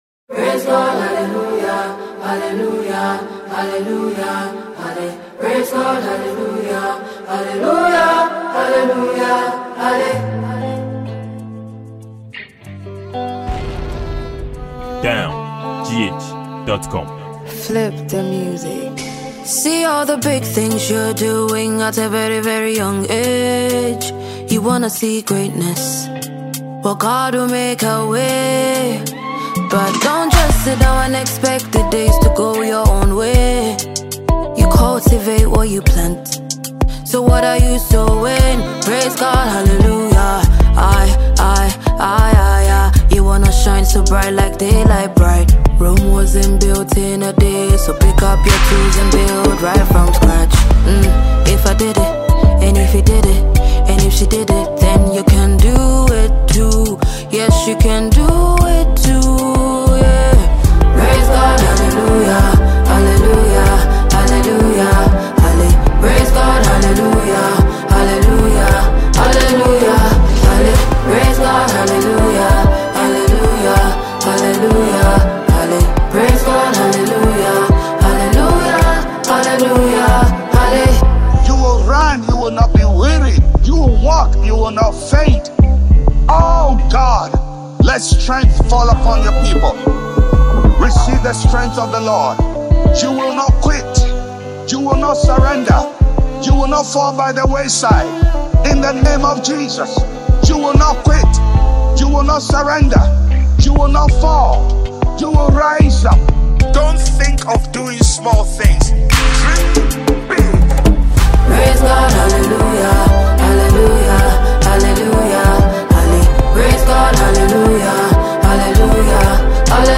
Ghana Music
Ghanaian singer and songwriter